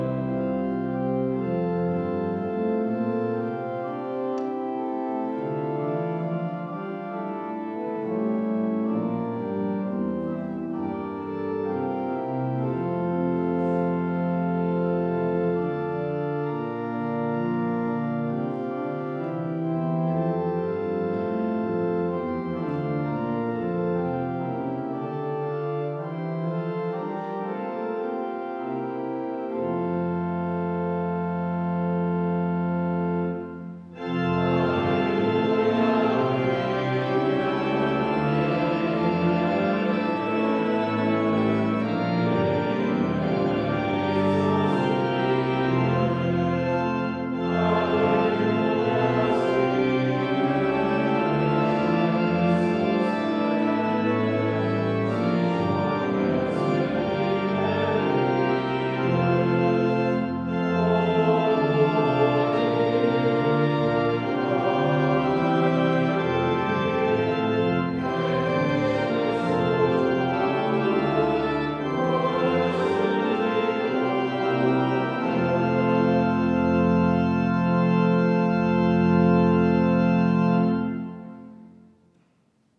Audiomitschnitt unseres Gottesdienstes vom 2. Sonntag nach Epipanias 2026.